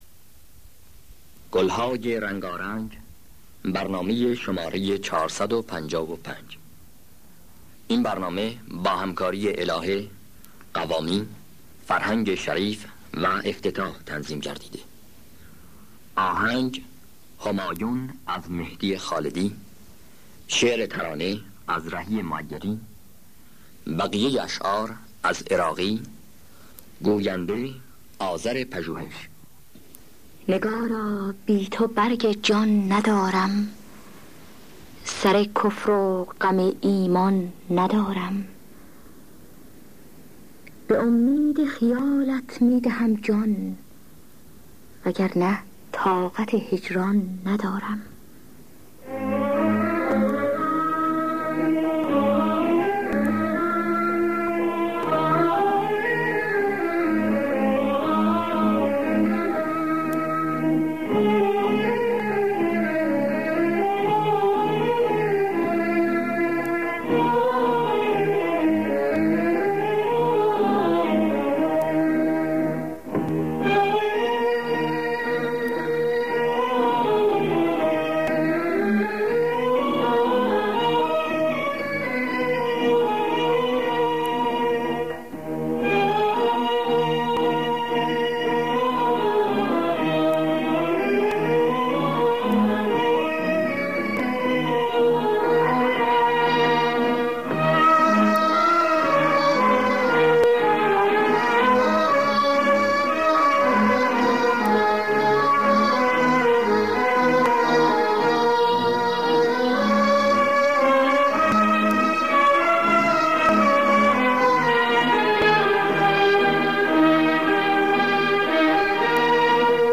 خوانندگان: الهه حسین قوامی